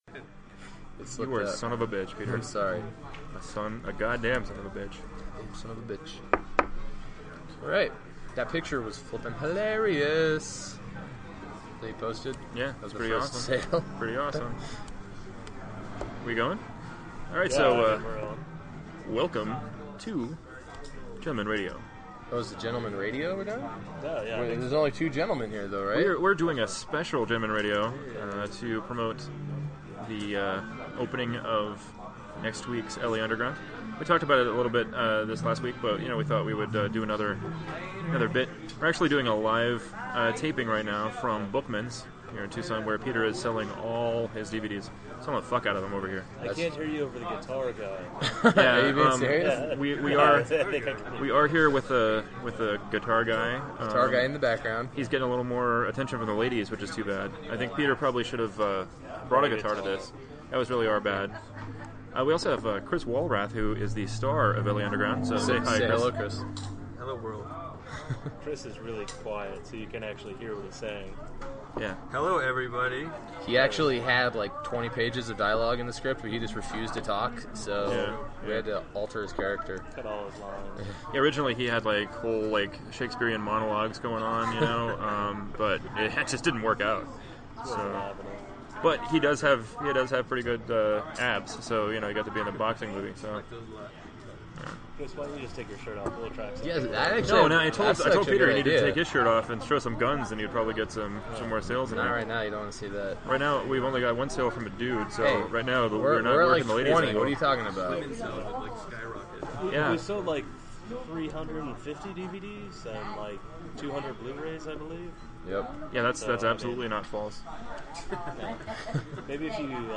Episode 18: Live From Bookman's
It is an experiment with remote equipment and an on-the-road formula.